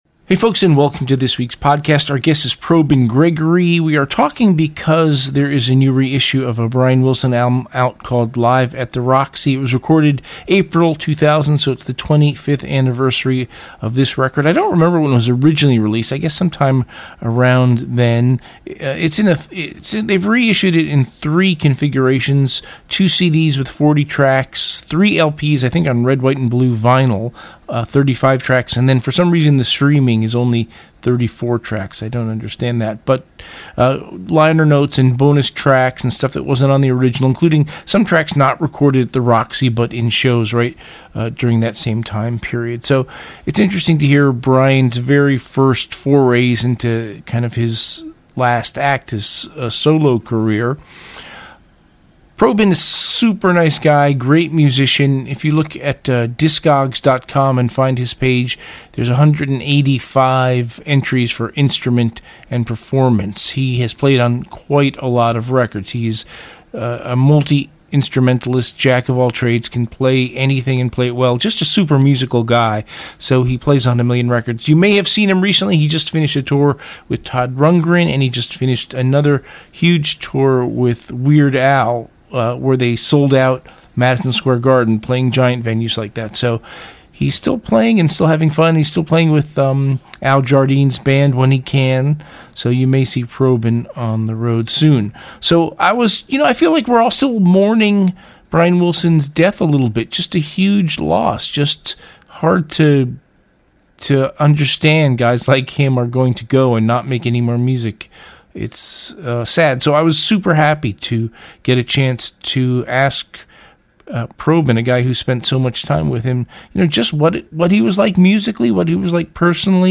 "Interview"https